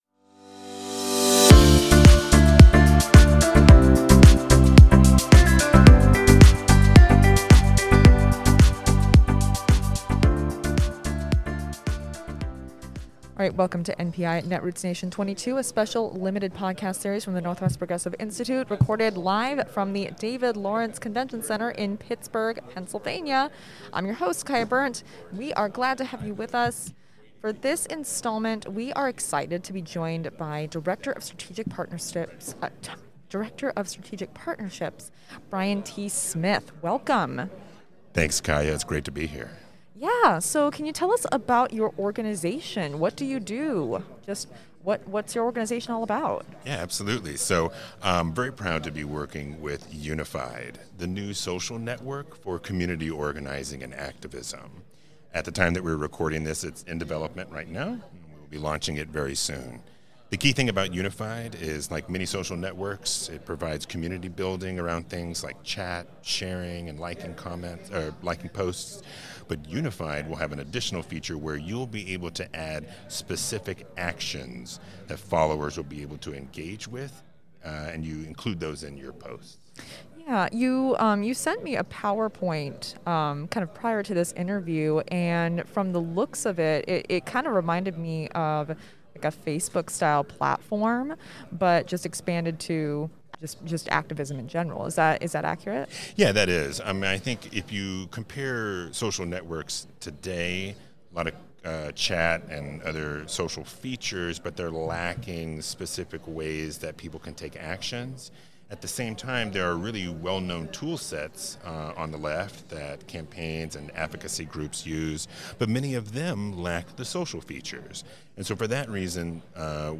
Welcome to the sixth installment of NPI at Netroots Nation 2022, a special limited podcast series recorded live from the David L. Lawrence Convention Center in Pittsburgh.
As part of our conference coverage, we’re bringing you a series of conversations with key movement leaders and elected officials.